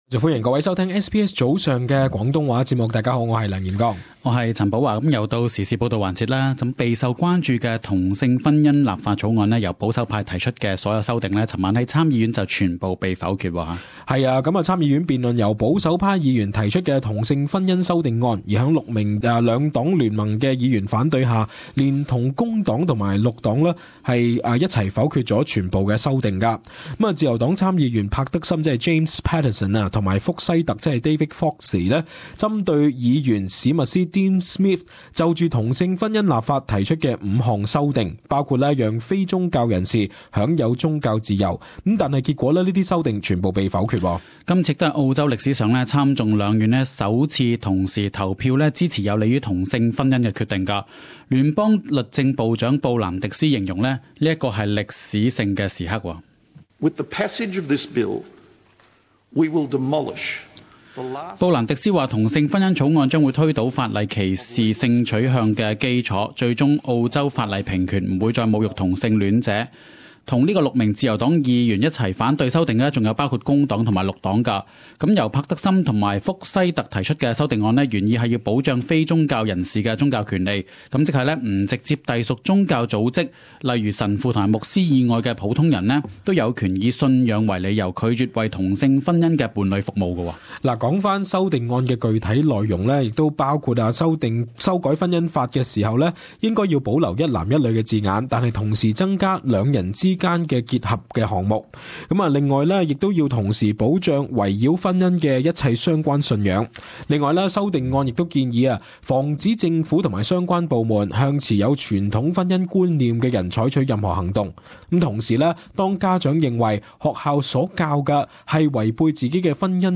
【PODCAST】時事報導：同性婚姻立法今日或過參院